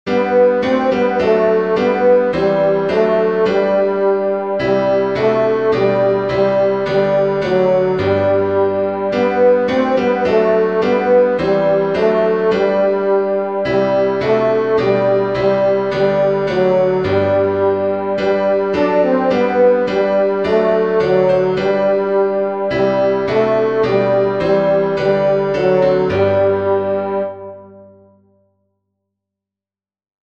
Tenor
dix_as_with_gladness_men_of_old-tenor.mp3